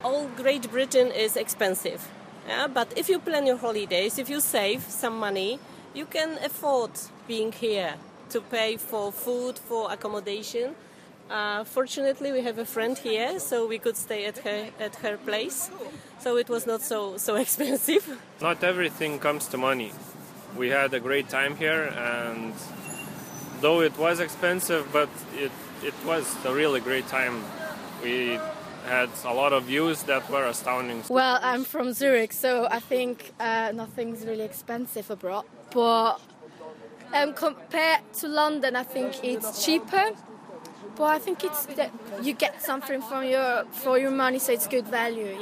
New figures reveal a rise in spending by overseas visitors. Forth News has been asking tourists in Edinburgh if they're getting value for money.